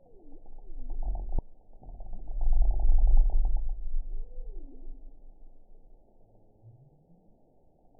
event 922102 date 12/26/24 time 10:49:51 GMT (11 months, 1 week ago) score 9.40 location TSS-AB06 detected by nrw target species NRW annotations +NRW Spectrogram: Frequency (kHz) vs. Time (s) audio not available .wav